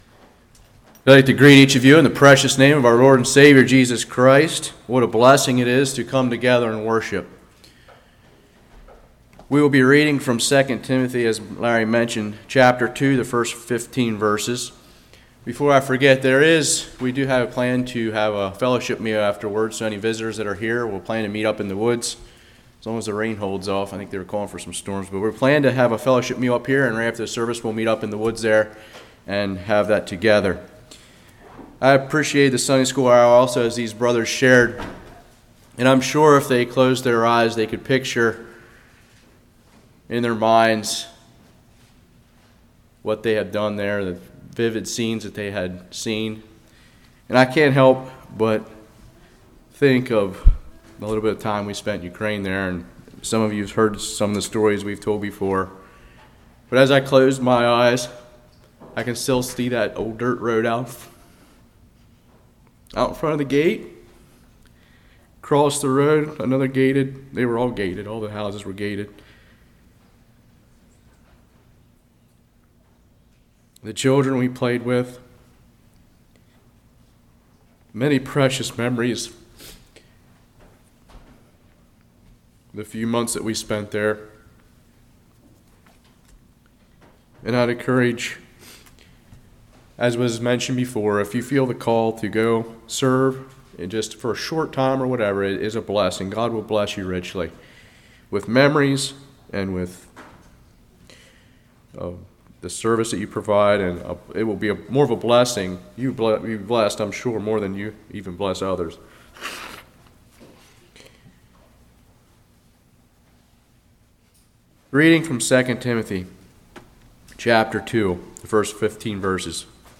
Sermon (Video: Start ~1:20 hr:min)